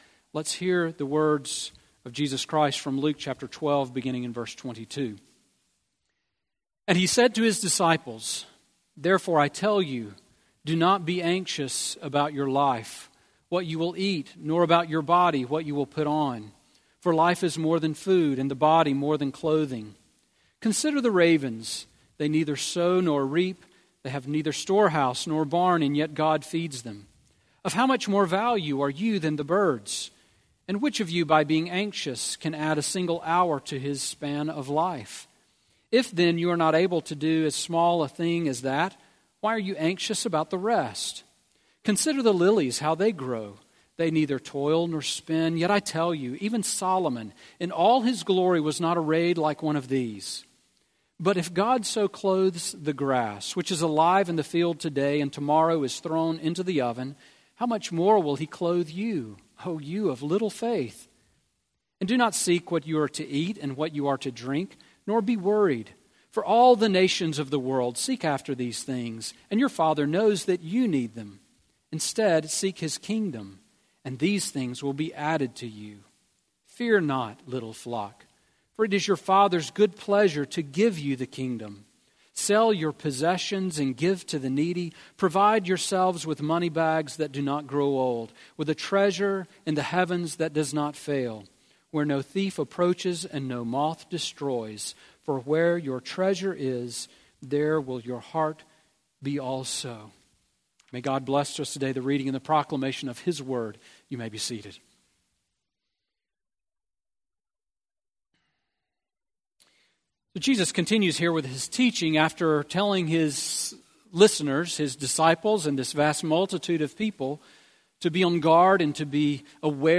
Sermon on Luke 12:22-34 from November 26